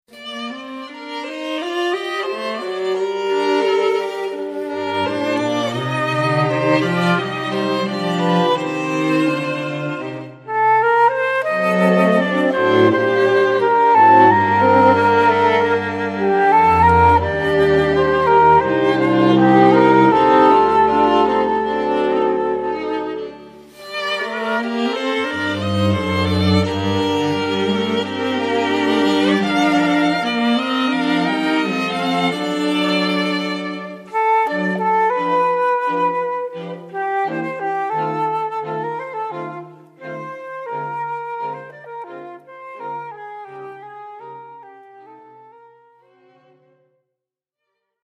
Musiche da camera